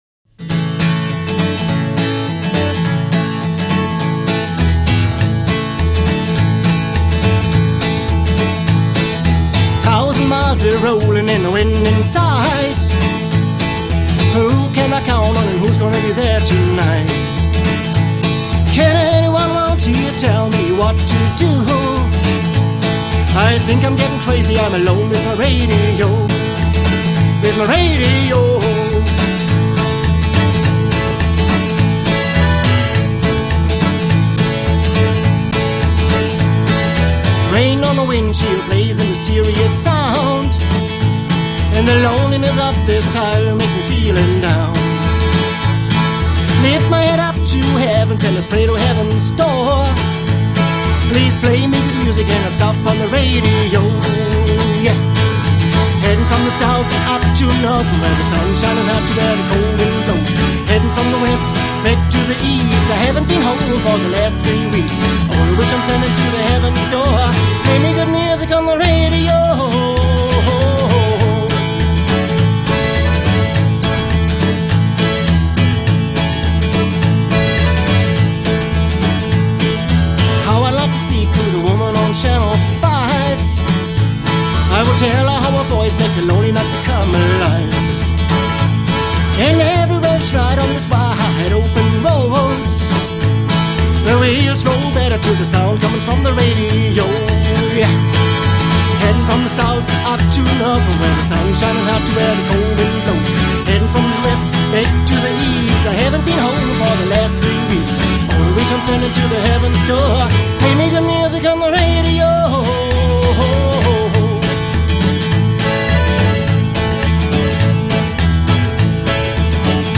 Good Rockin Acoustic Music